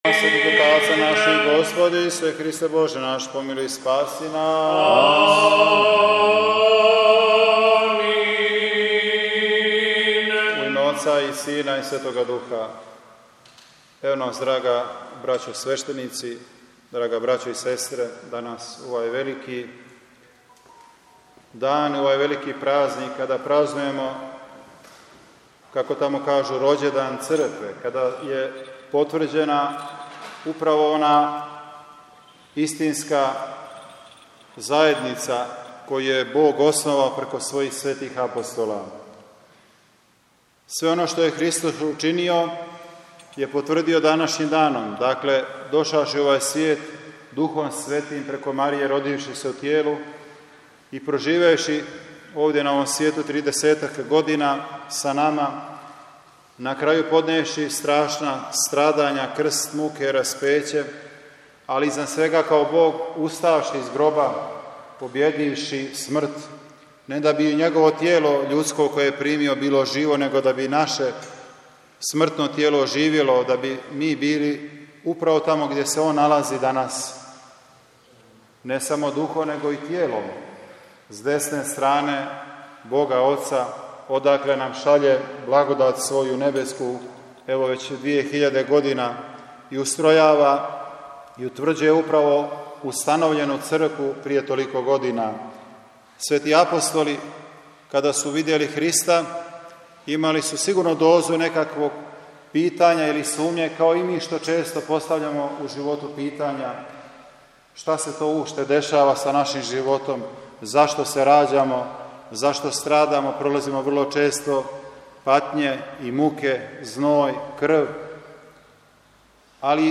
Звучни запис беседе
Његово Преосвештенство Епископ Сергије обратио се присутном народу литургијском бесједом.
Бесједа-Његовог-Преосвештенства-Епископа-Сергија-у-Бихаћу.mp3